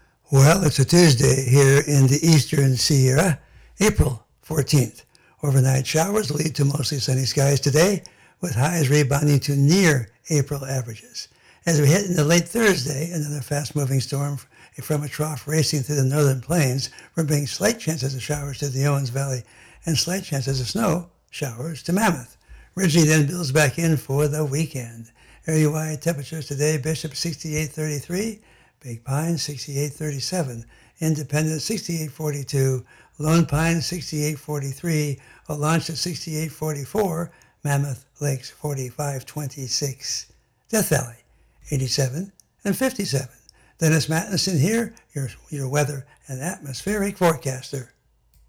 Area Forecast Discussion